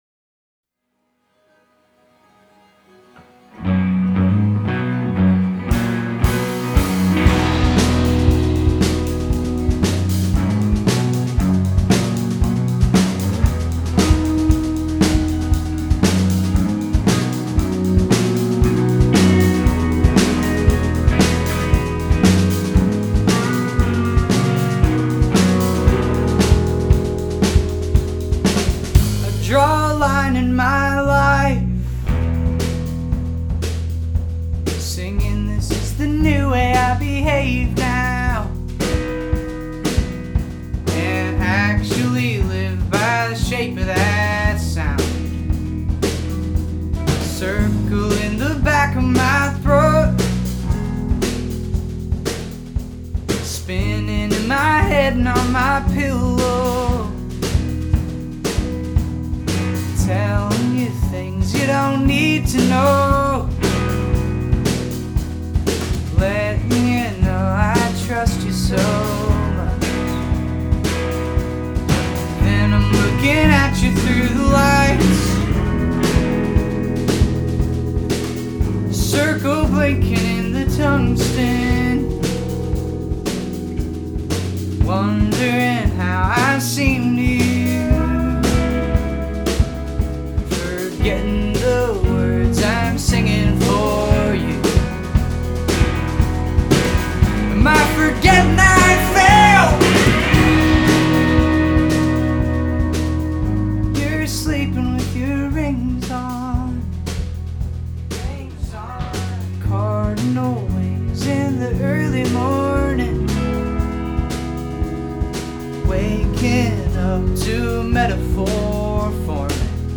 Adepte d'une pop éthérée et lumineuse
réminiscences émo, folk et country